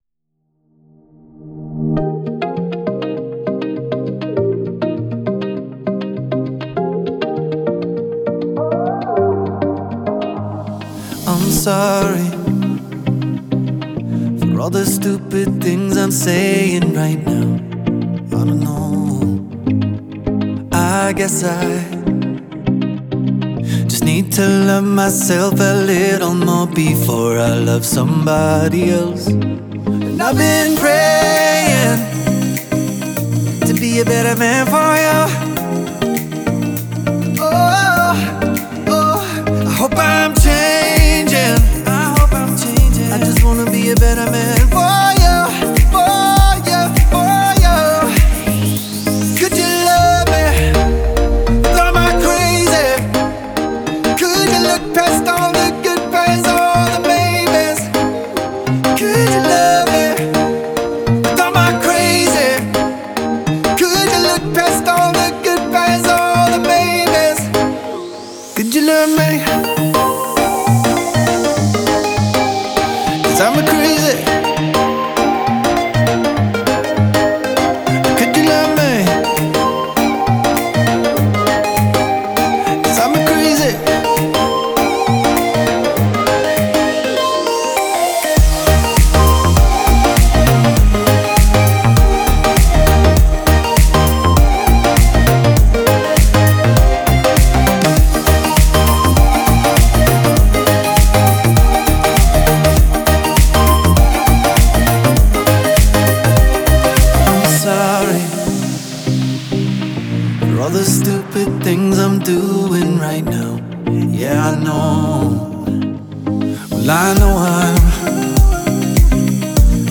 это трек в жанре электронной поп-музыки